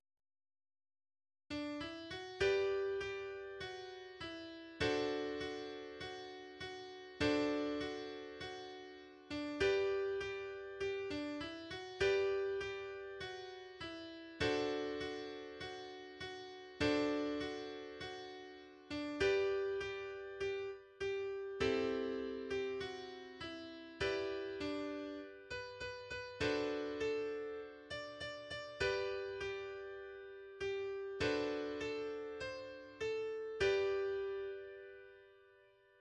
Text & Melodie Volkslied